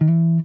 SLIDESOLO2.wav